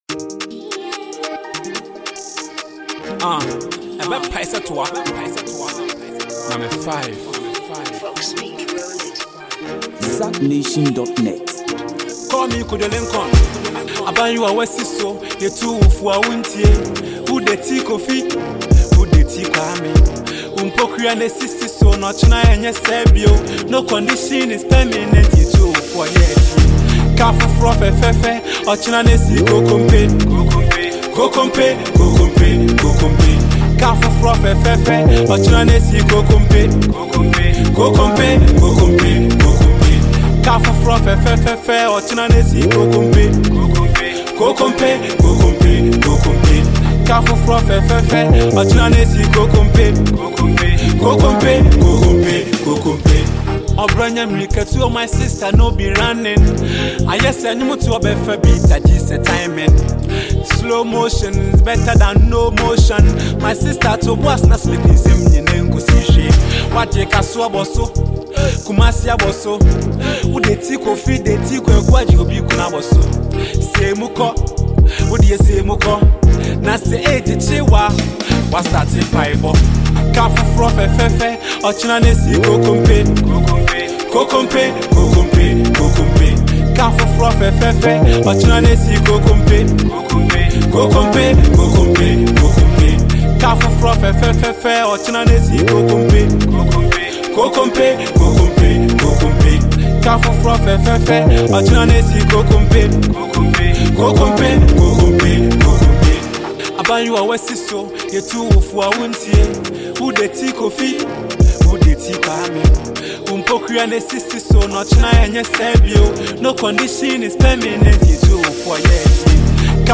With his smooth vocals and catchy lyrics